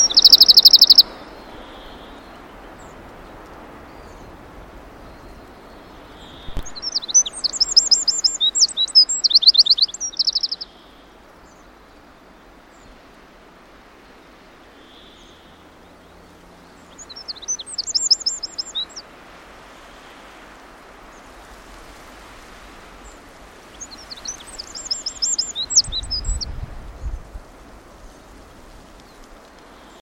Photos de Troglodyte mignon - Mes Zoazos
Manteau brun sur chemise pâle, nullement complexé par sa taille minuscule, c’est un oiseau pétulant et furtif qui aime se faire entendre.
troglodyte.mp3